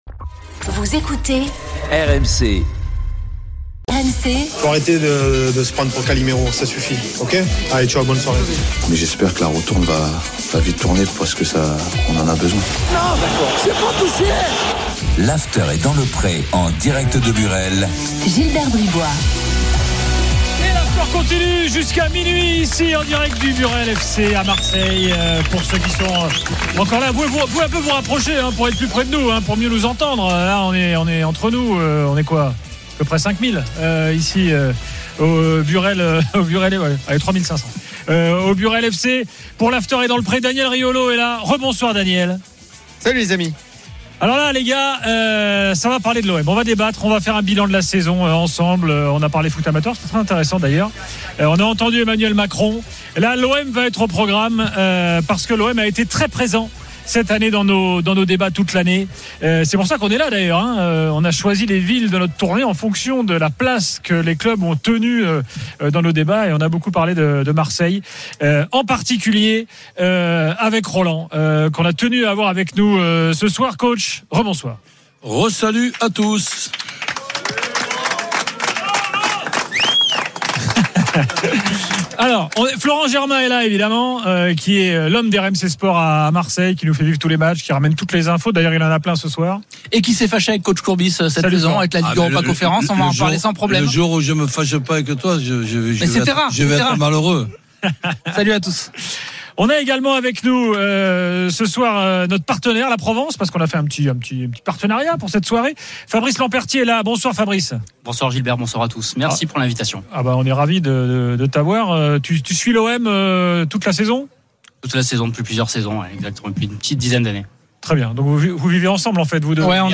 Chaque jour, écoutez le Best-of de l'Afterfoot, sur RMC la radio du Sport !